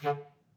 Clarinet / stac
DCClar_stac_D2_v2_rr1_sum.wav